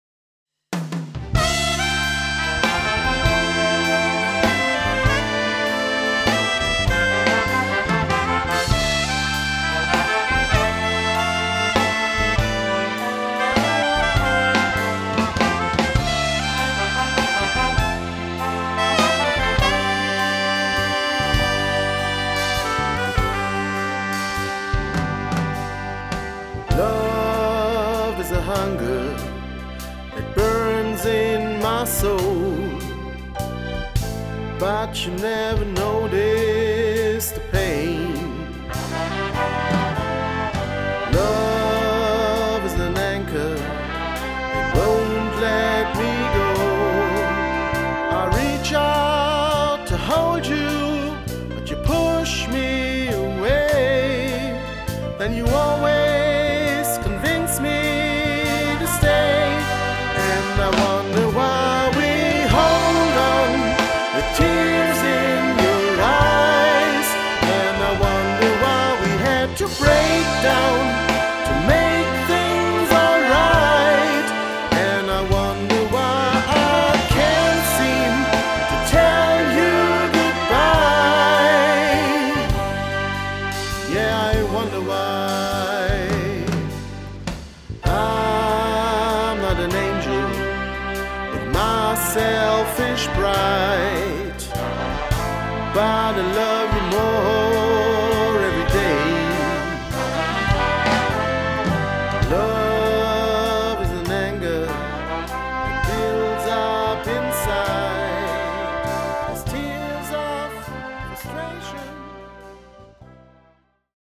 Live - Mitschnitte (Auszüge)